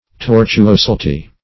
Search Result for " tortuoslty" : The Collaborative International Dictionary of English v.0.48: Tortuoslty \Tor`tu*os"l*ty\, n. [L. tortuositas: cf. F. tortuosite.] the quality or state of being tortuous.